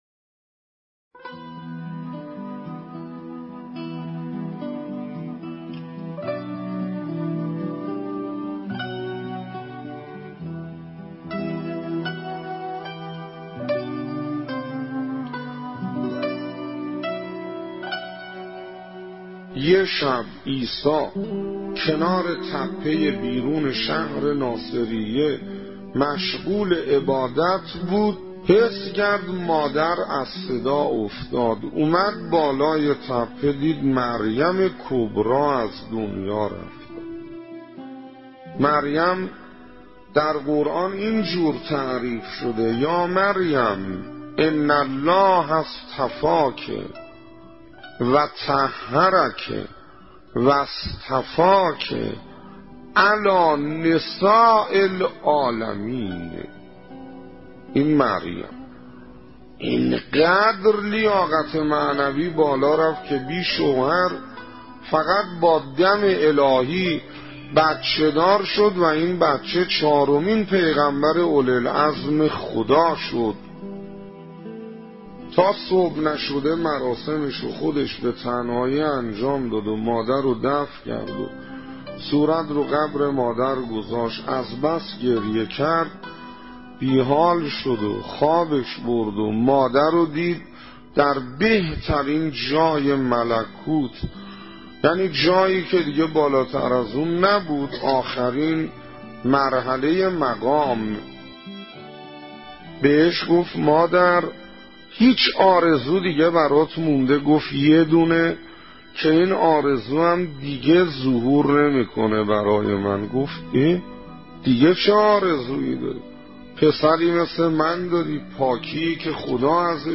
نواهنگ نماز از حجت الاسلام انصاریان